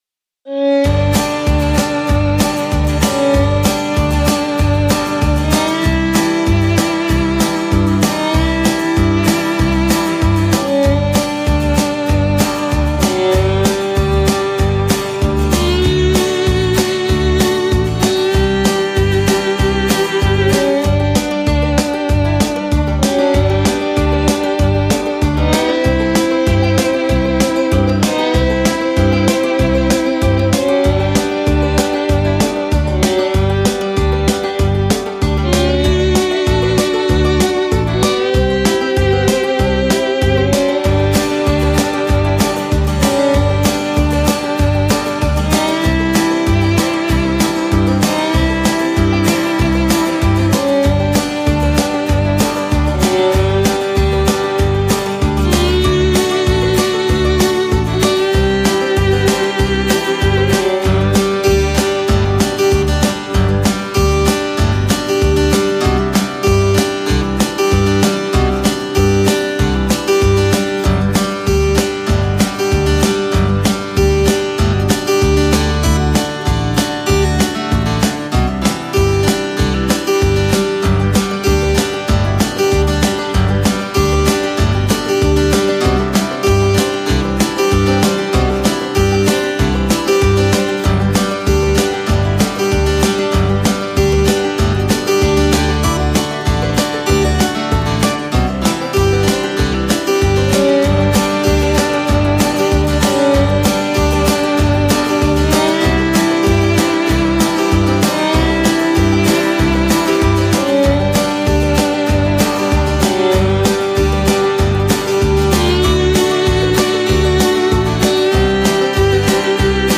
это захватывающая песня в жанре кантри